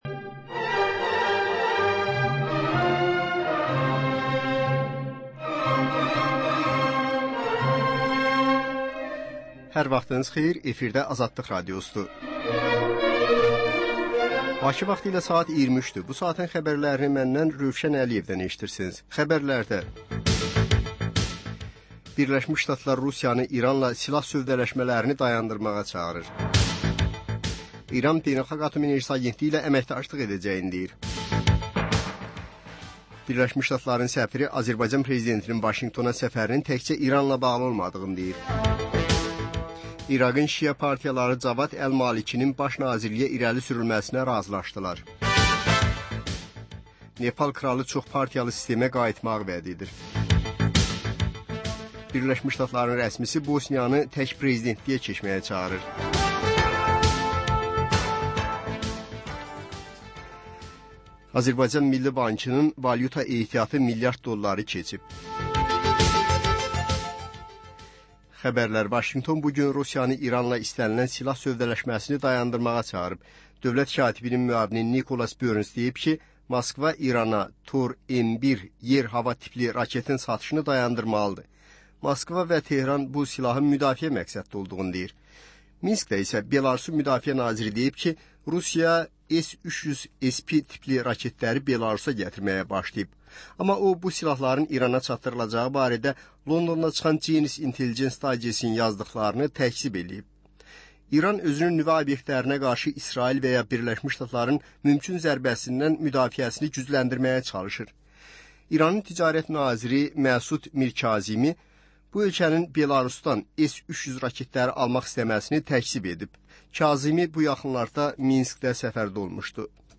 Xəbərlər, reportajlar, müsahibələr. Və: Günün Söhbəti: Aktual mövzu barədə canlı dəyirmi masa söhbəti.